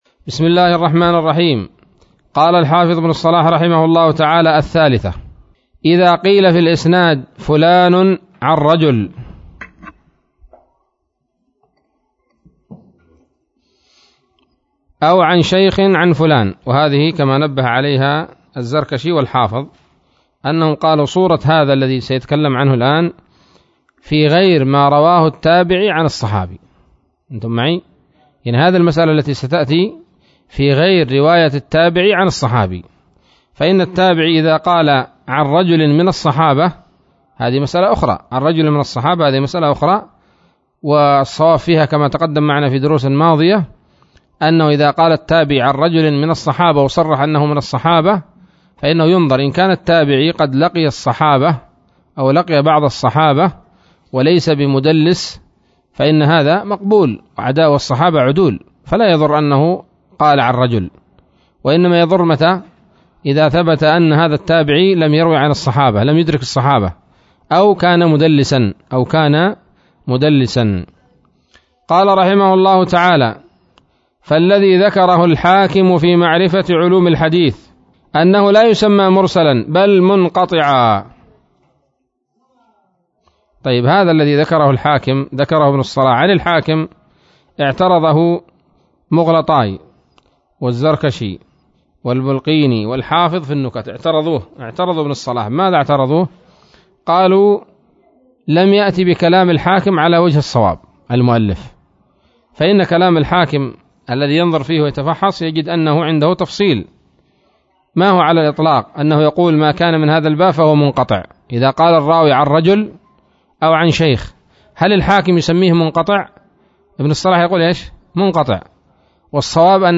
الدرس الرابع والعشرون من مقدمة ابن الصلاح رحمه الله تعالى